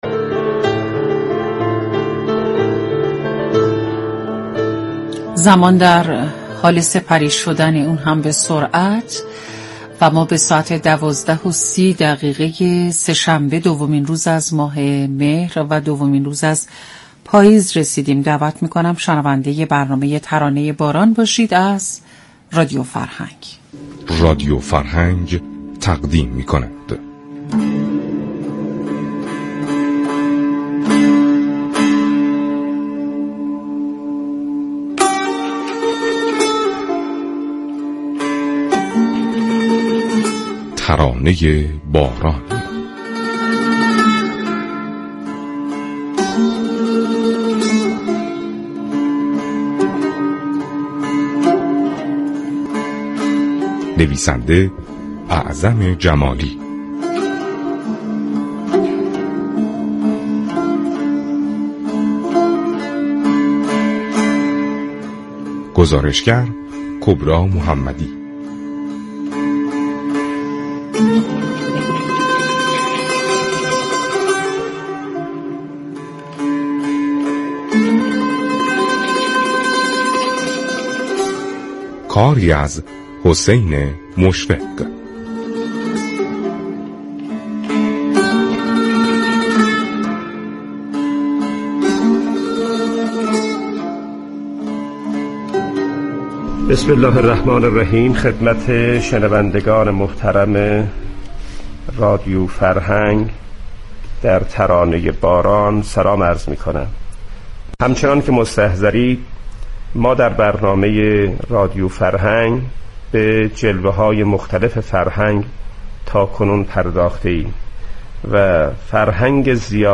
برنامه ترانه باران امروز ساعت 12:30 در آستانه شهادت امام سجاد(ع) با یكی از چاووشی خوانان خراسان شمالی گفتگو كرد.
در این برنامه كه به بررسی فرهنگ زیارت خراسان جنوبی اختصاص داشت درباره چاووشی خوانی در مناسبت های مختلف از جمله بازگشت حجاج از سفر حج و همچنین زیارت امام رضا (ع) مستندی را پخش كرد كه یكی از چاووشی خوانان به اجرای قطعاتی از این چاووشی ها پرداخت.